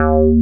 BASS30  01-L.wav